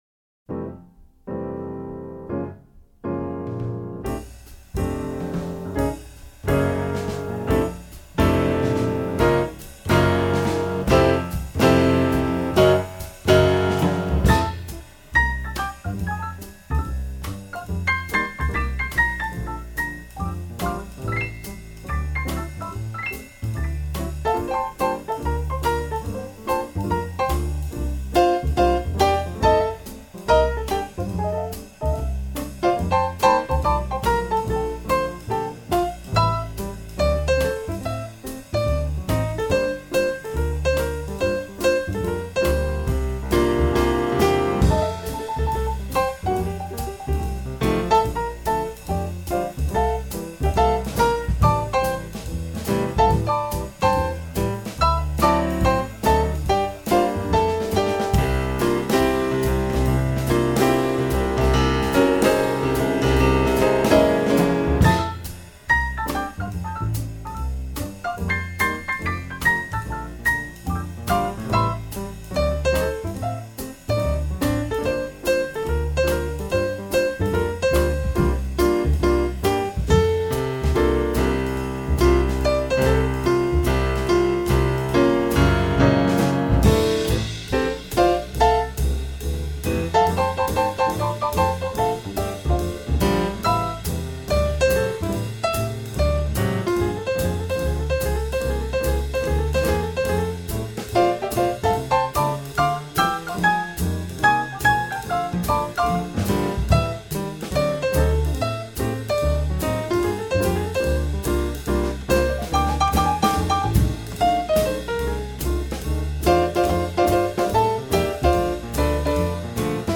钢琴的明亮、大提琴的牛筋味，爵士鼓的恰倒好处。
这个录音是在日本的东京进行的，美国压片,一面 双层SACD版本。